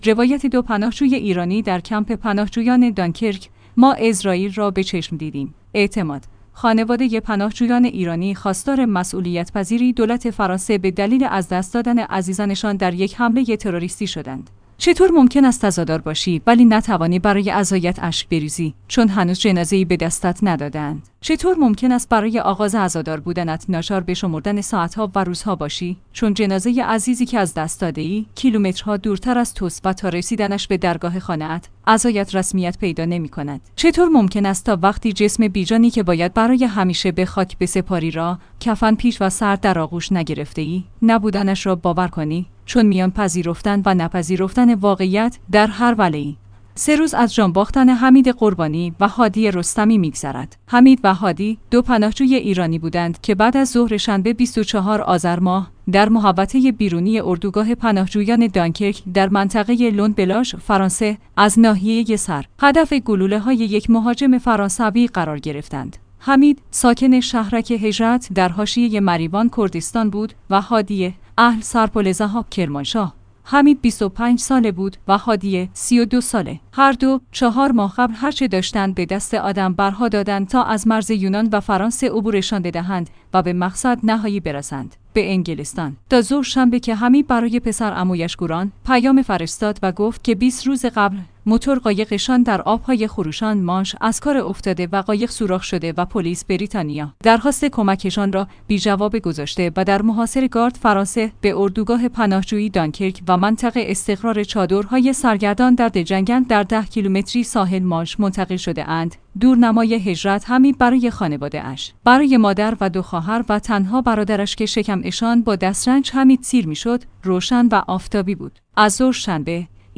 روایت دو پناهجوی ایرانی در کمپ پناهجویان «دانکرک»: ما عزراییل را به چشم دیدیم